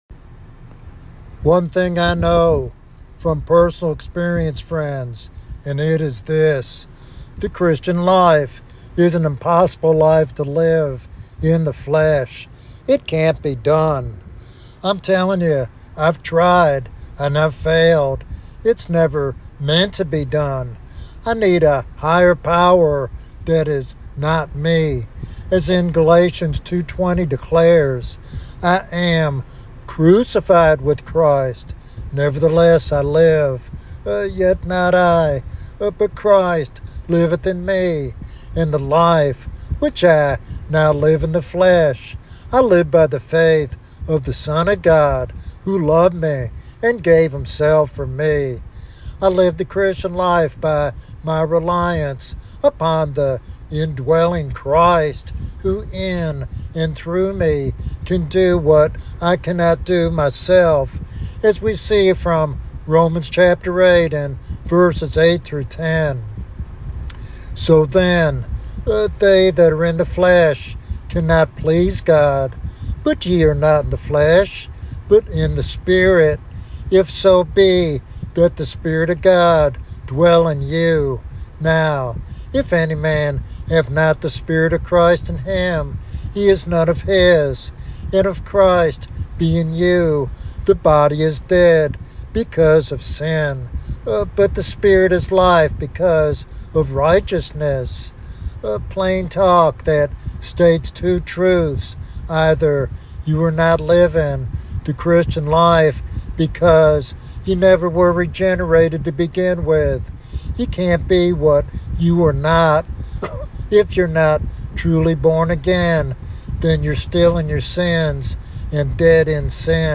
In this powerful expository sermon